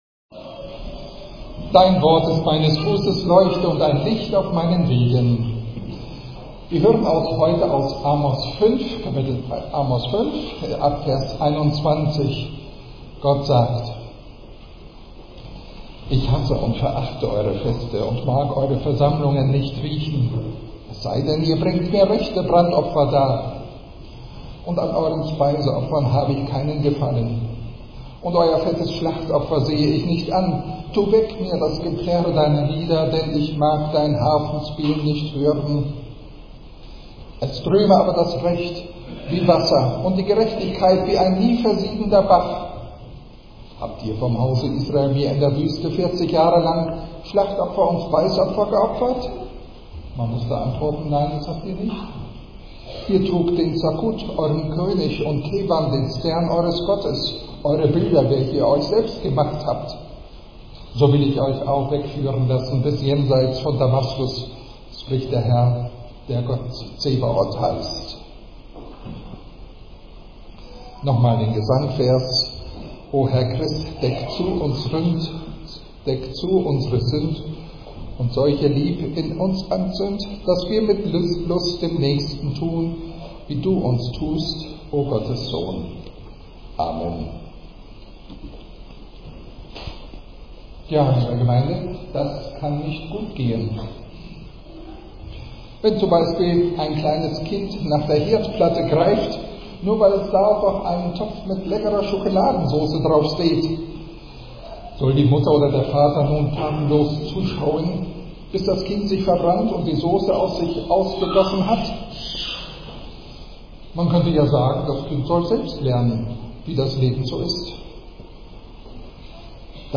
Lutherische Gemeinde Lüneburg Lutheran Congregation - Sermon Predigt ESTOMIHI (Mit sehenden Augen) | FELSISA - Wochenspruch.